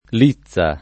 lizza [ l &ZZ a ]